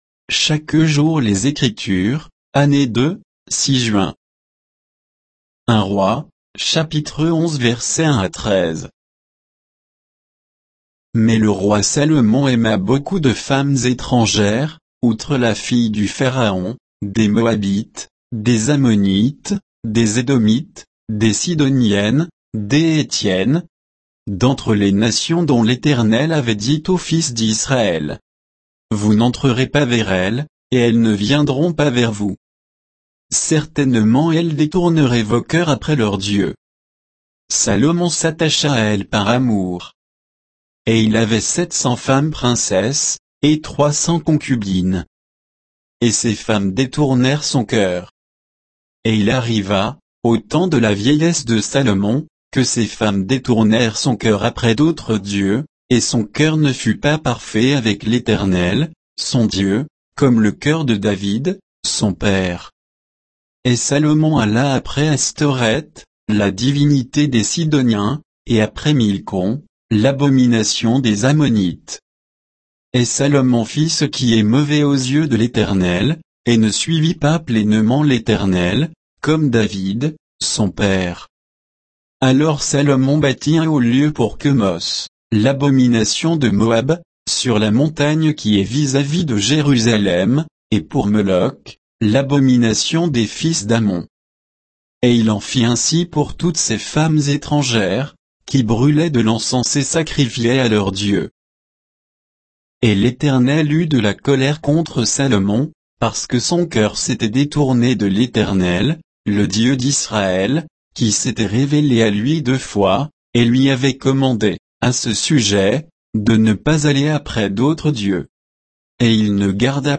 Méditation quoditienne de Chaque jour les Écritures sur 1 Rois 11